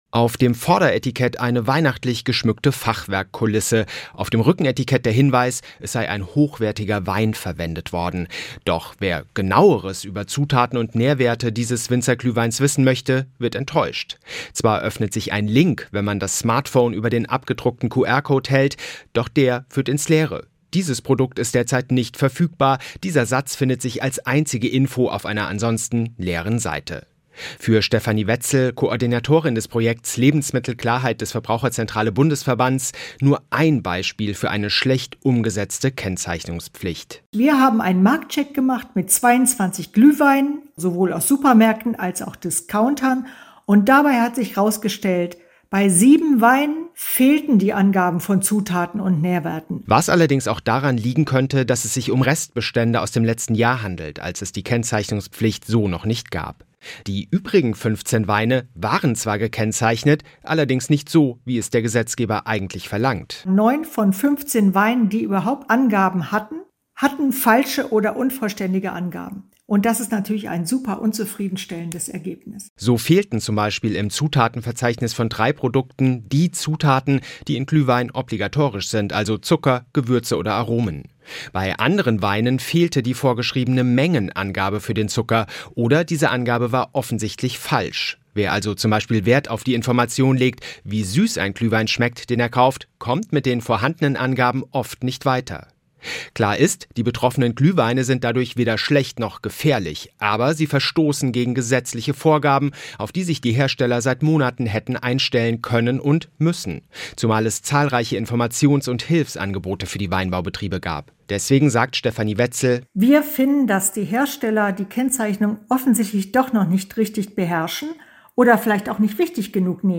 Nachrichten „Hersteller nehmen Kennzeichnung von Glühwein offensichtlich nicht wichtig“